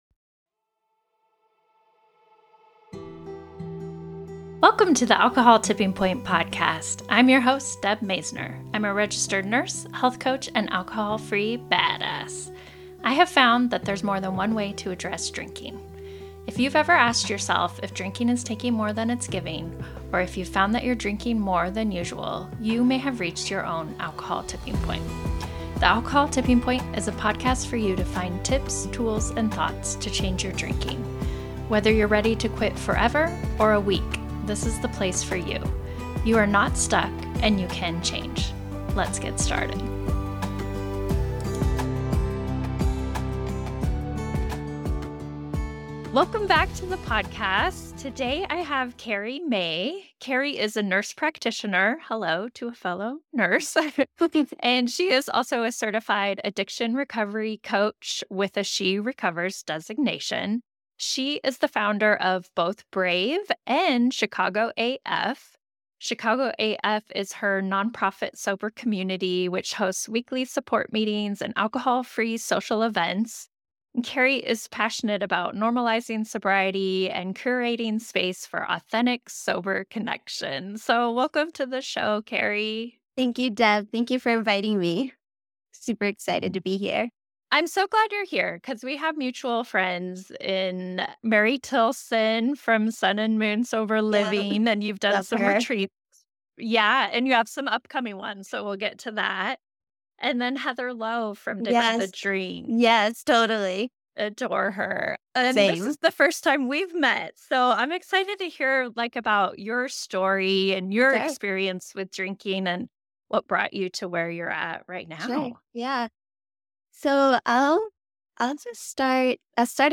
Overcoming the Stigma of Alcohol Problems in Healthcare: Interview